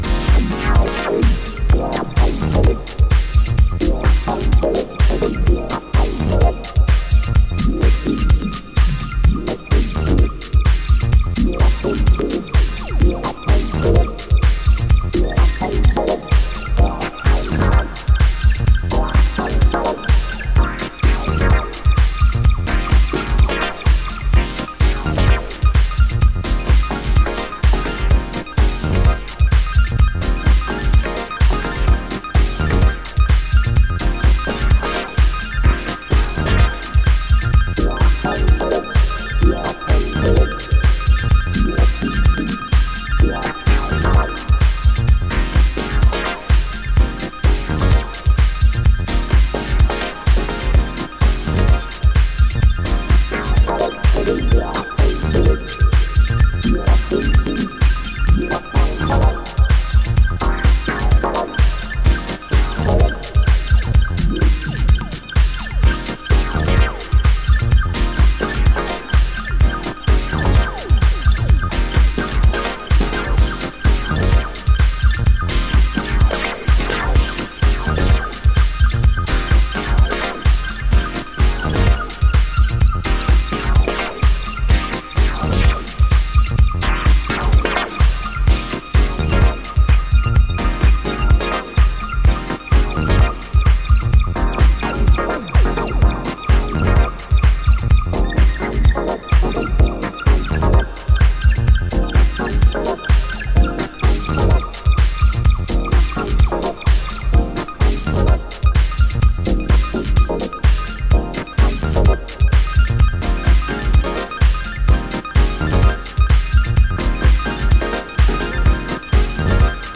House / Techno
アシディックフロートする